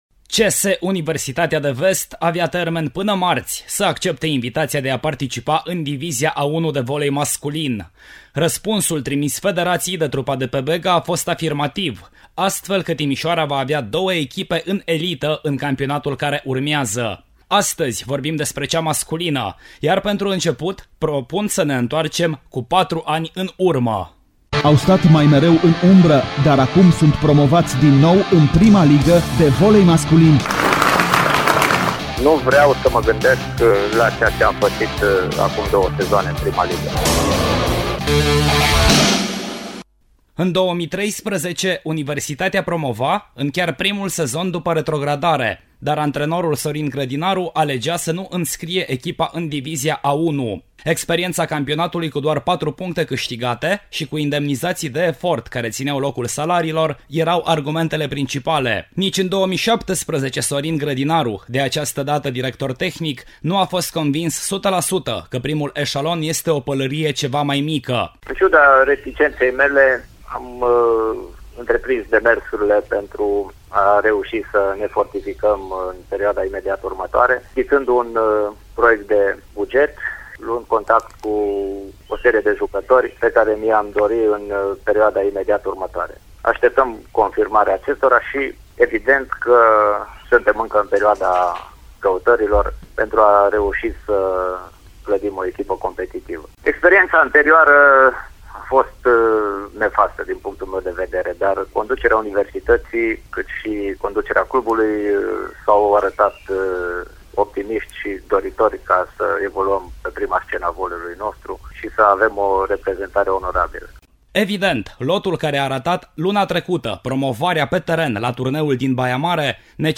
Reportajul